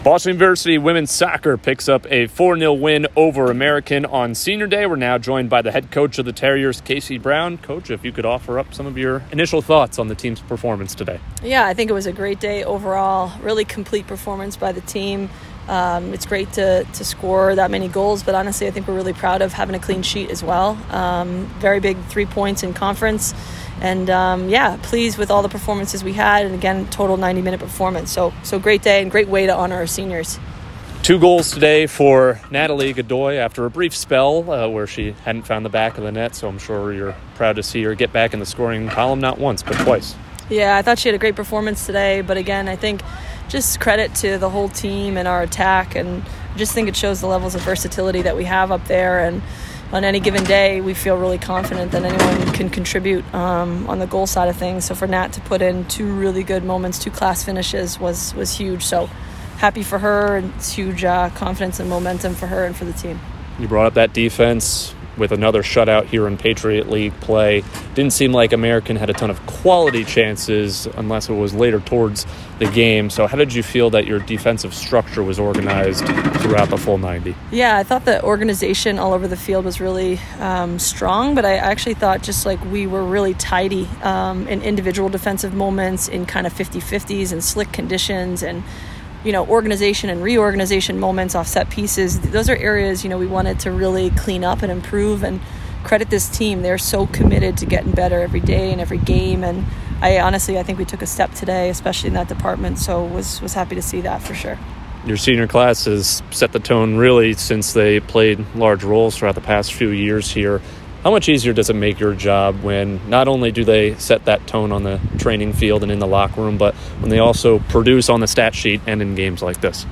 WSOC_American_Postgame.mp3